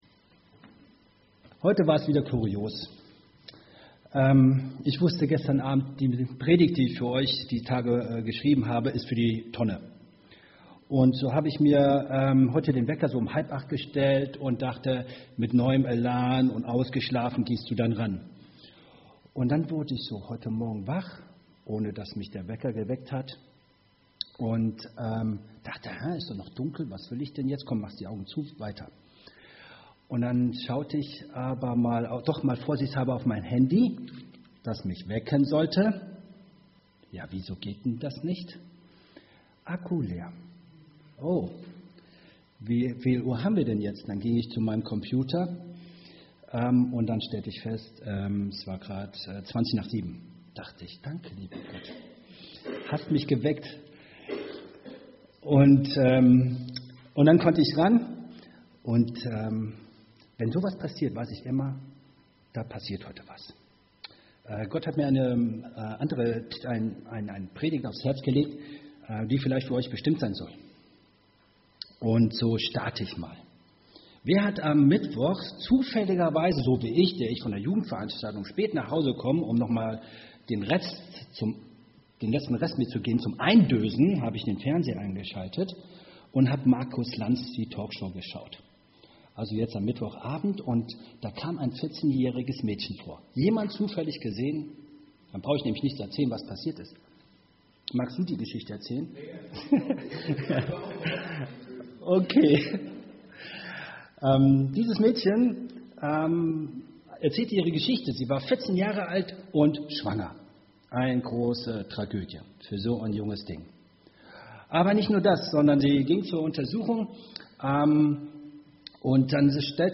Evangelisch-freikirchliche Gemeinde Andernach - Predigt anhören